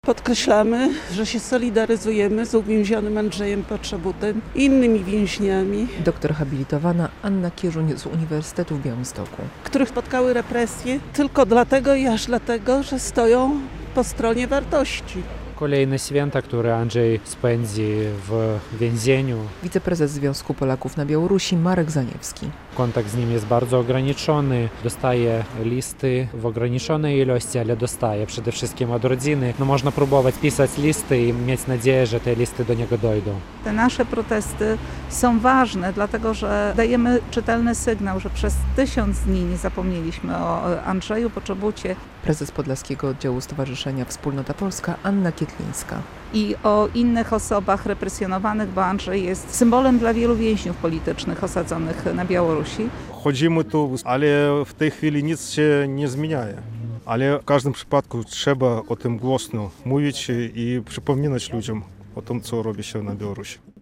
Pikieta odbywa się raz w miesiącu i to było 31. spotkanie.